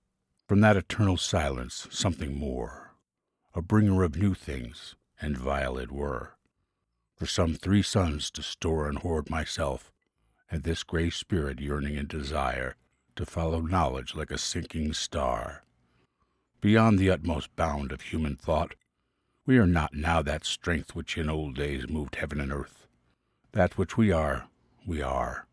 Male
Documentary
Wise Elder Poetic Emotional
0525Poetic_read_with_emotion_Demo.mp3